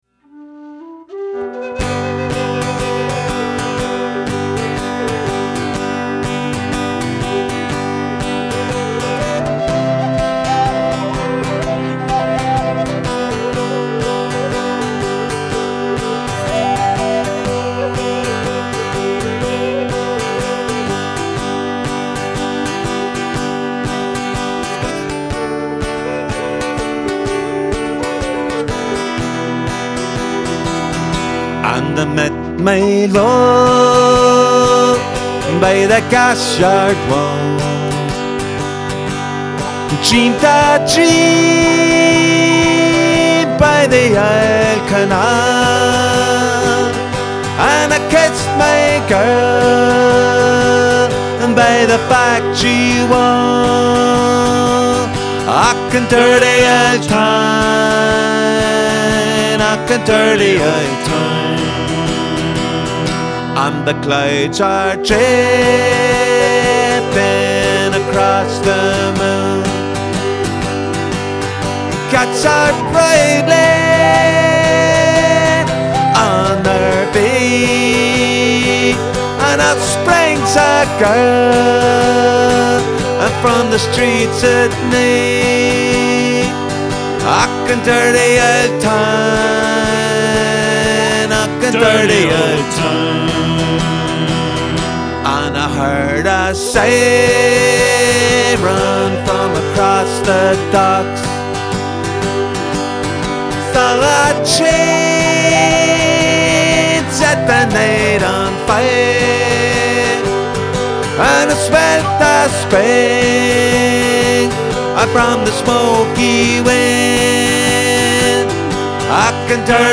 A la recherche d’une vraie ambiance de pub irlandais ?
Guitariste, harmoniciste et chanteur.
Batteur, percussionniste et chanteur.